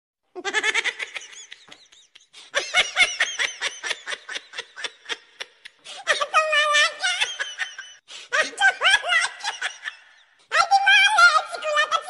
Dog Laughing Meme Sound Effect Free Download
Dog Laughing Meme